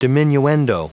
Prononciation audio / Fichier audio de DIMINUENDO en anglais
Prononciation du mot diminuendo en anglais (fichier audio)